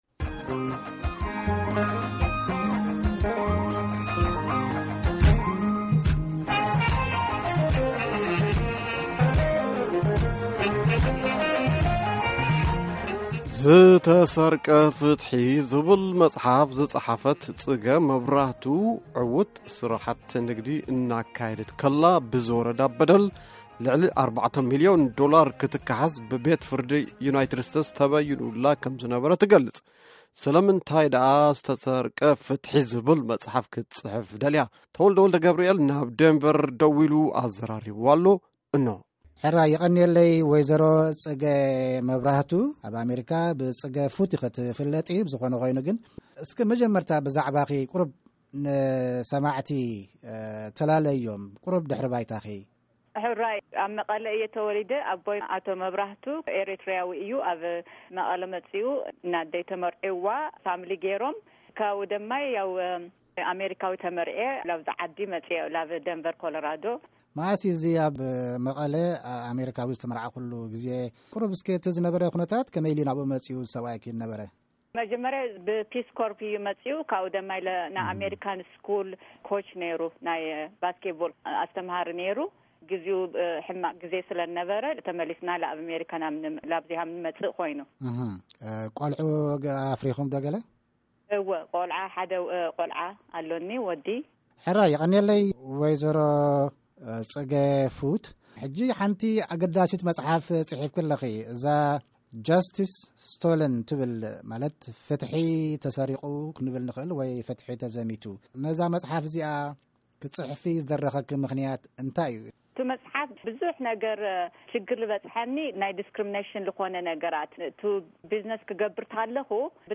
ሙሉእ ቃለ-መጠይቅ